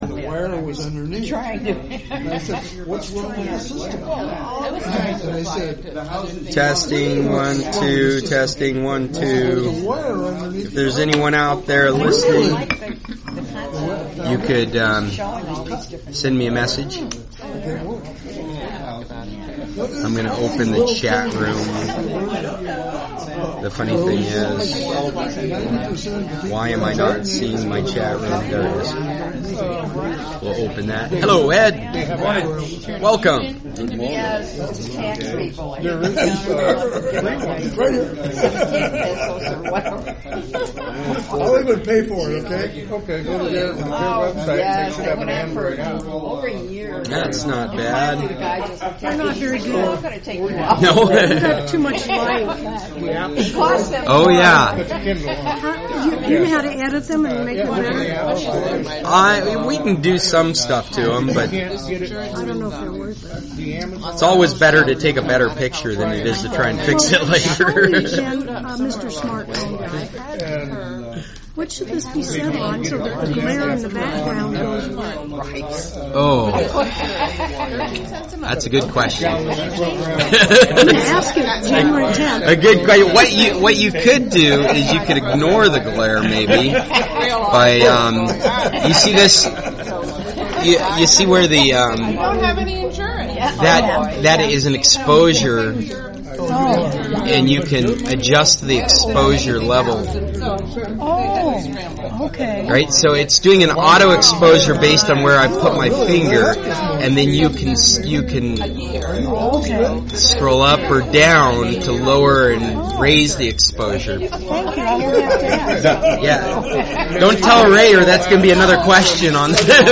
December 18, 2018 :11: Last Meeting of 2018, See You Next Year!